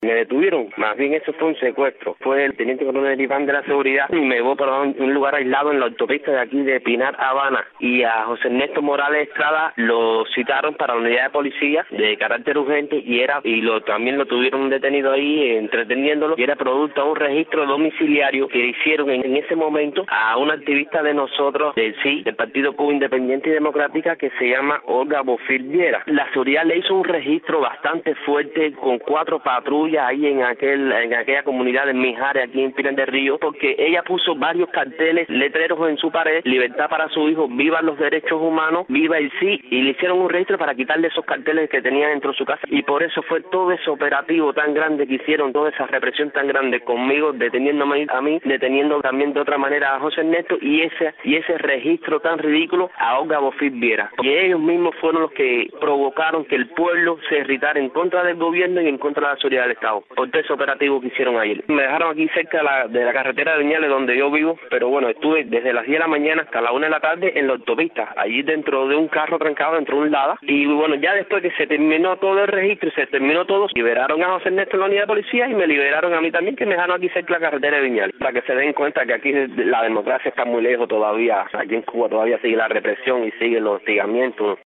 Reporta Cuba.